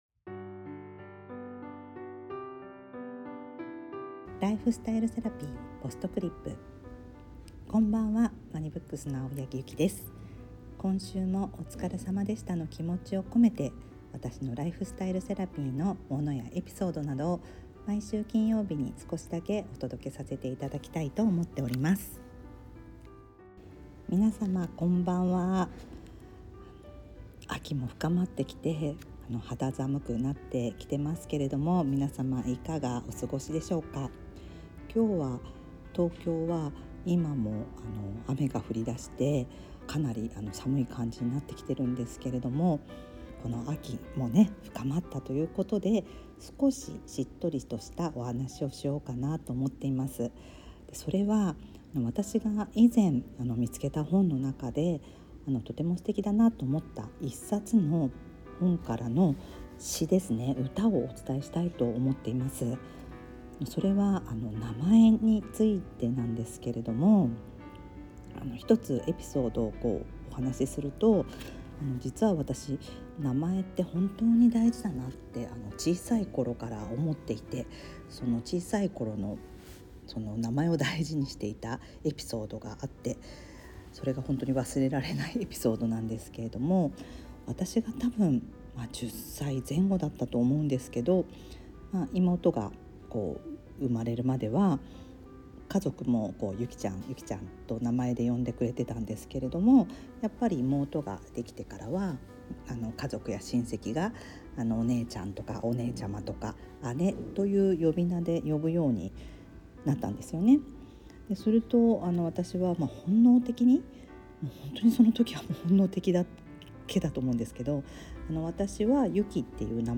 今回は、『親から子へ伝えたい17の詩』（双葉社刊）より、毛里武さんの作品「名前は祈り」を朗読しながら、「名前の贈りもの」についてお話ししました。
BGM／MusMus